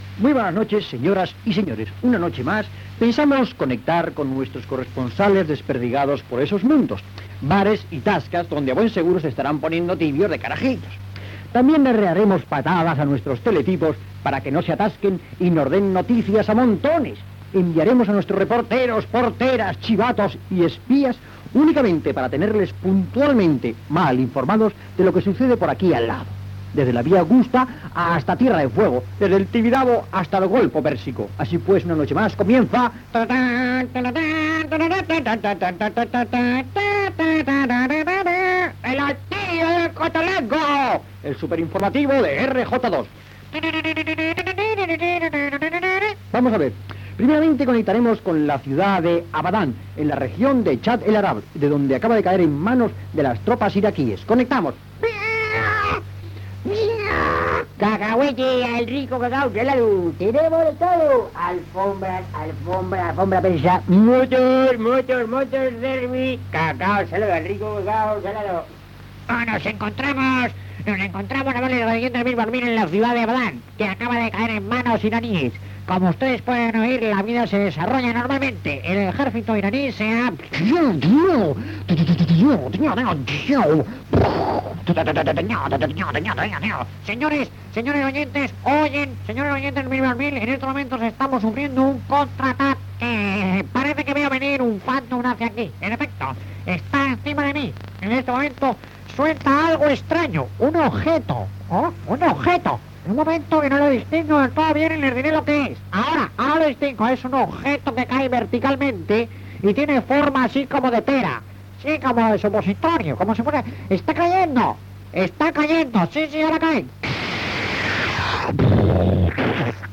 "El altillo del cotolengo", informatiu humorístic
Entreteniment
FM